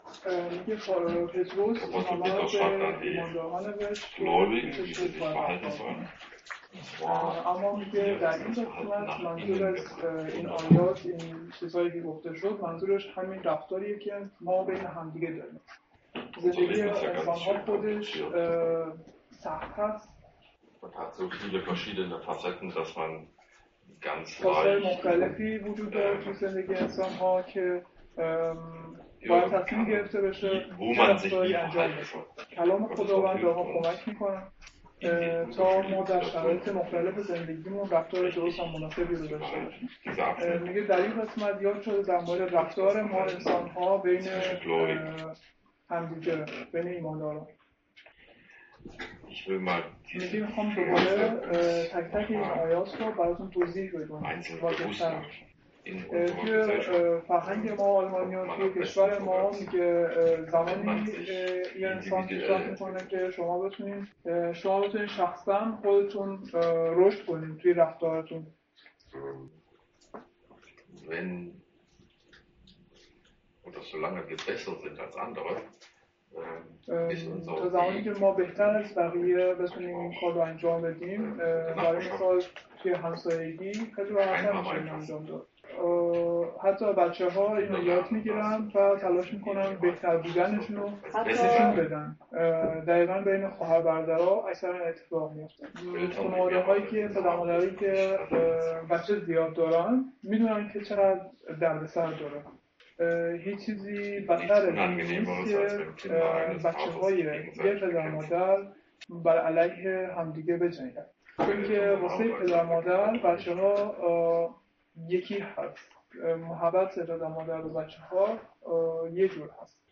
Petrus Kapitel 3, 8-16 |Predigt vom 05.02.2017 in deutsch und Farsi